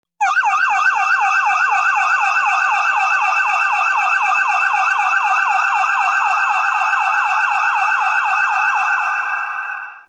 Download Sirens sound effect for free.
Sirens